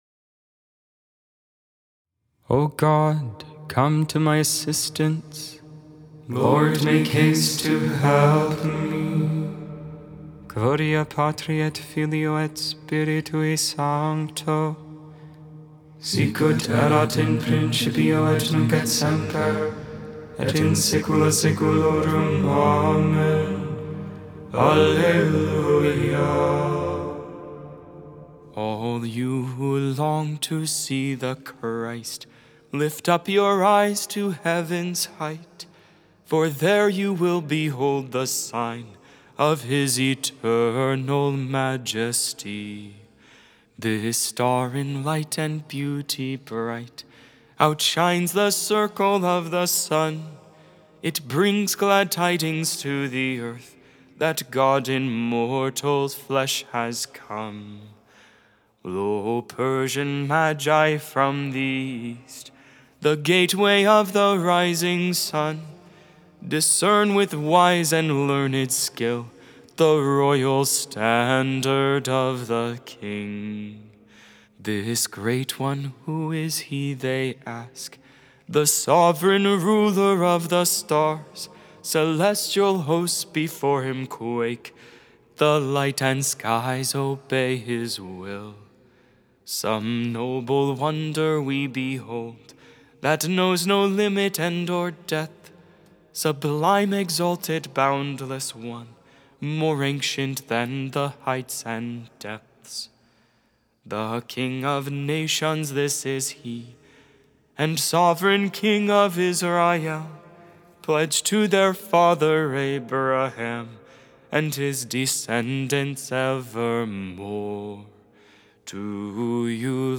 1.10.25 Lauds, Friday Morning Prayer of the Liturgy of the Hours